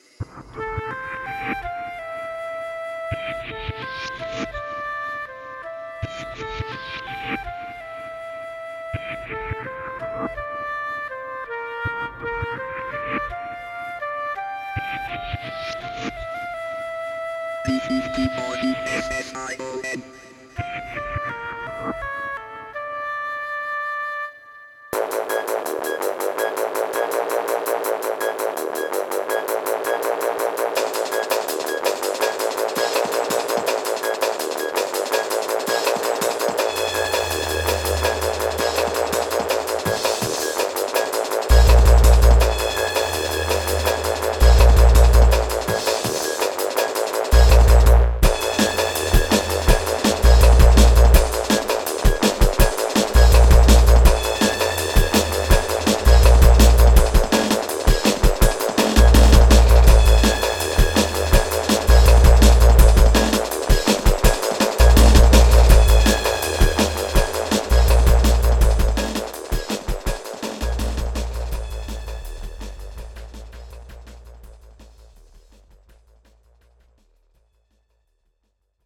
play mp3 clip   Drum'n'Bass with a techy attitude